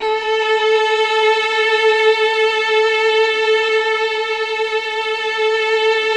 MELLOTRON .8.wav